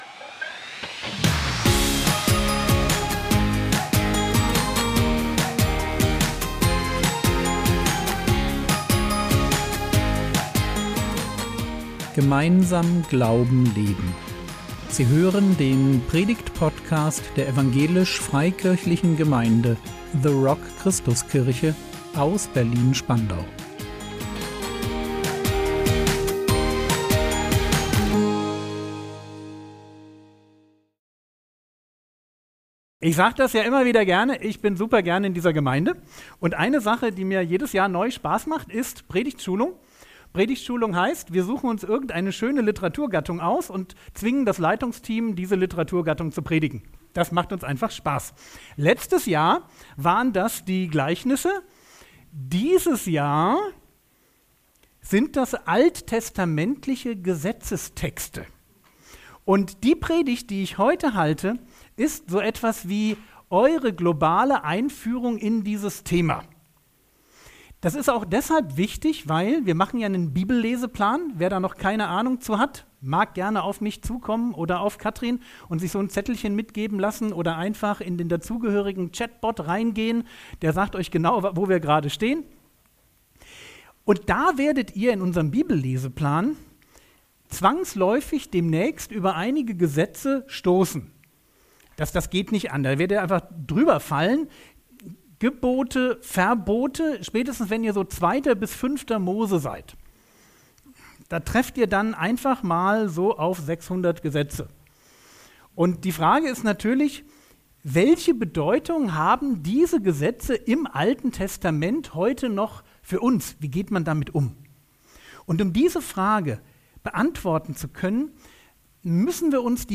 Die Gesetze des AT gelten nicht mehr - oder doch? | 16.02.2025 ~ Predigt Podcast der EFG The Rock Christuskirche Berlin Podcast